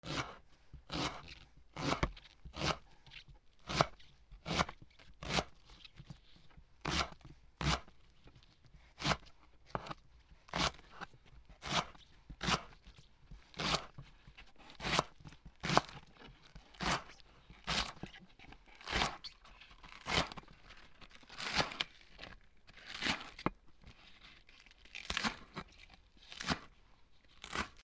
ねぎを切る 料理
R26mix